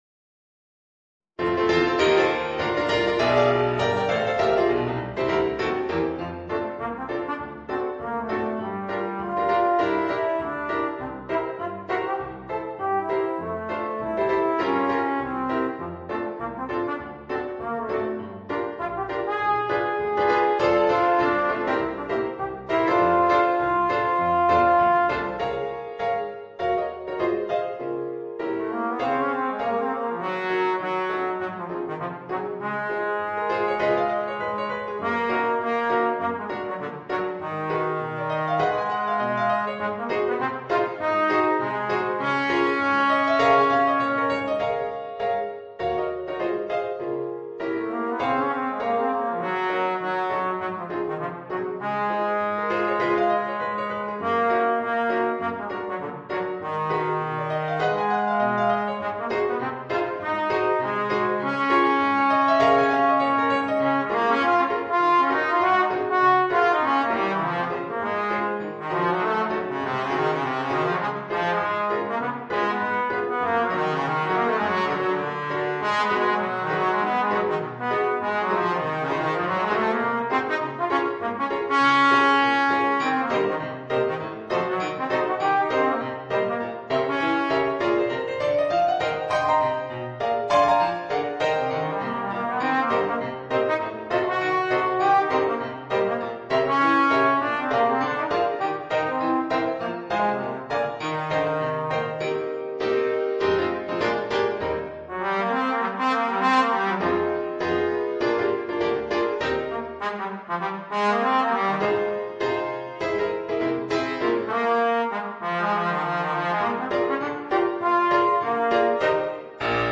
Trombone & Piano